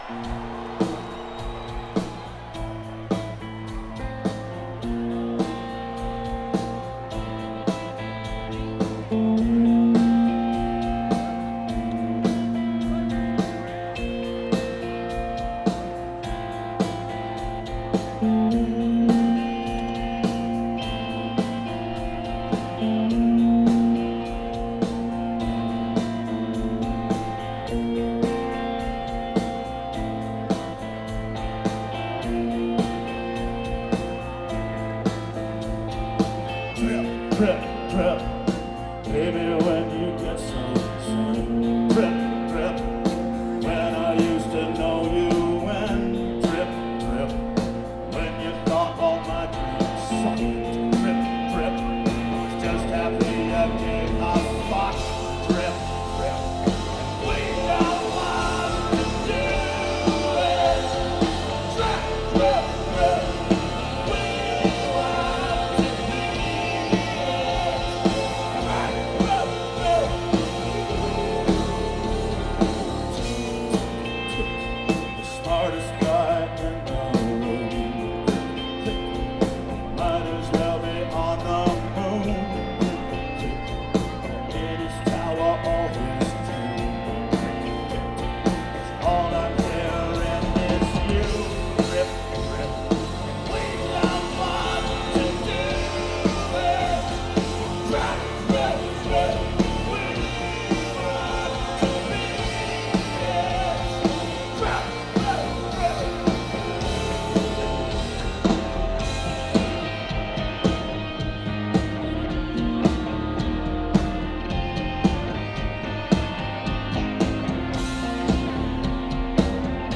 (12th Live Performance)